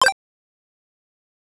Chiptune Sample Pack
8bit_FX_C_02_02.wav